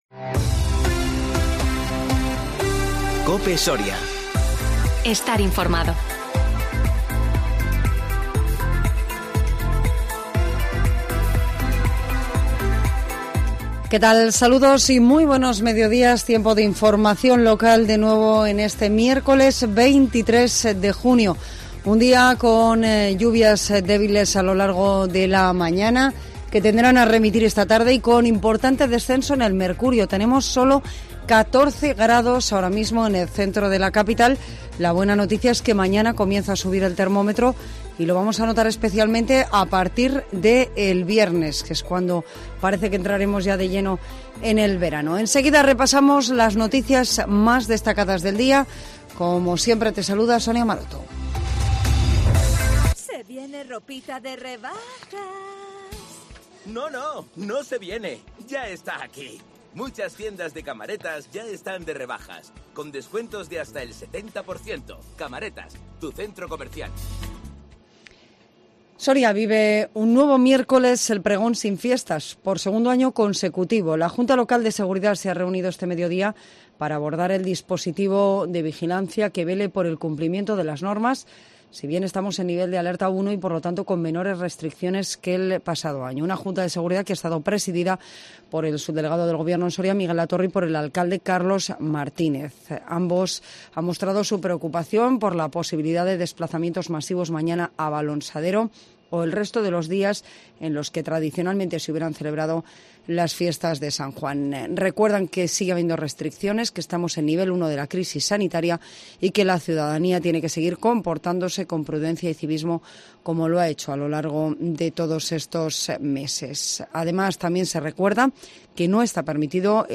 INFORMATIVO MEDIODÍA 23 JUNIO 2021